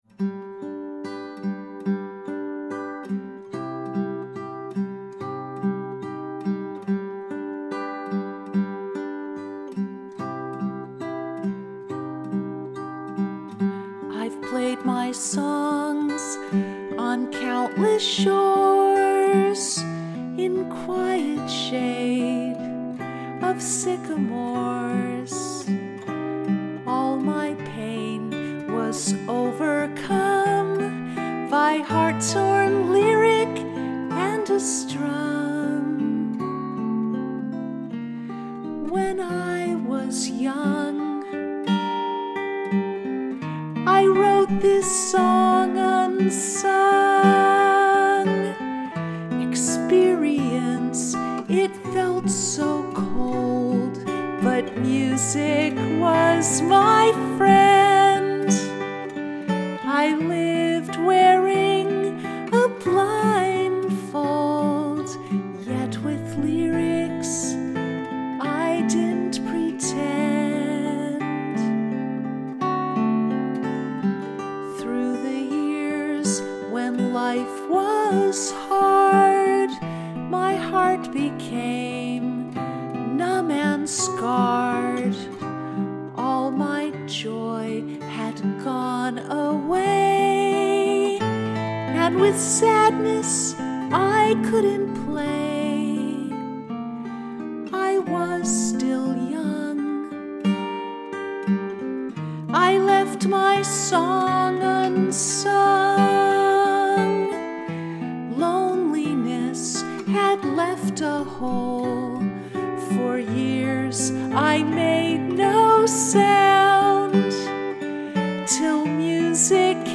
My Song Unsung Home Recording 2018
my-song-unsung-home-recording-6-29-18.mp3